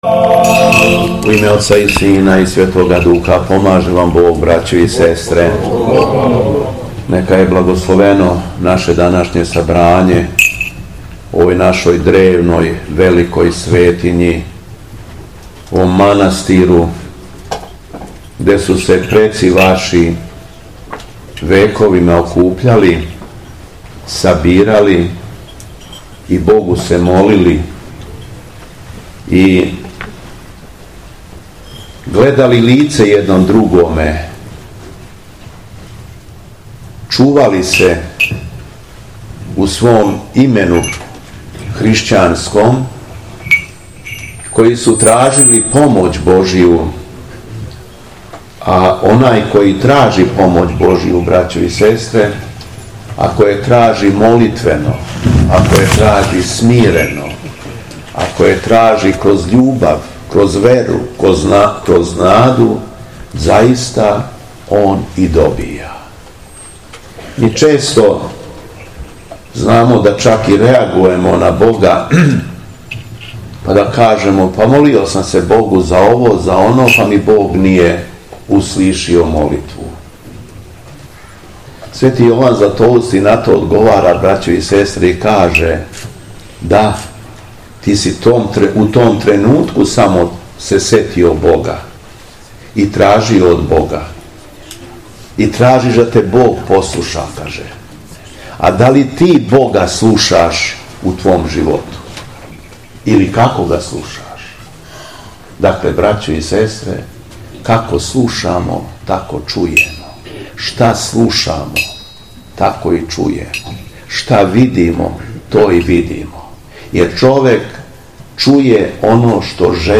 У суботу, 2. септембра 2023. године, када наша света Црква прославља Светог пророка Самуила и Светог свештеномученика Филипа Ираклијског, Епископ шумадијски Г. Јован служио је свету Литургију у манастиру Ивковић, посвећен рођењу Пресвете Богородице у Ивковачком Прњавору.
Беседа Његовог Преосвештенства Епископа шумадијског г. Јована